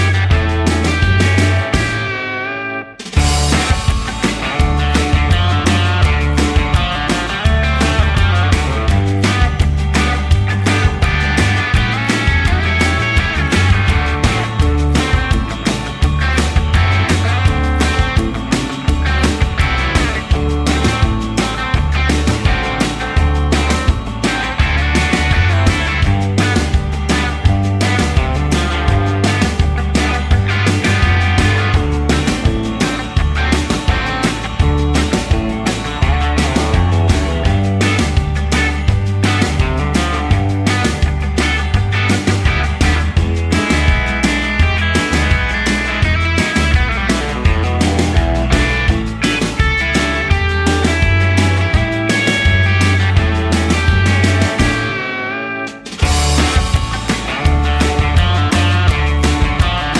no Backing Vocals Jazz / Swing 3:55 Buy £1.50